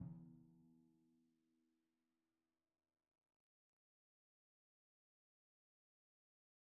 Timpani4_Hit_v1_rr1_Sum.wav